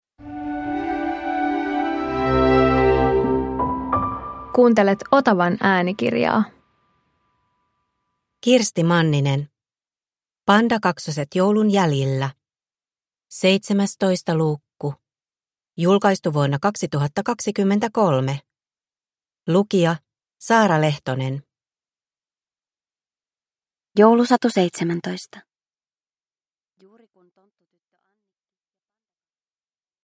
Pandakaksoset joulun jäljillä 17 – Ljudbok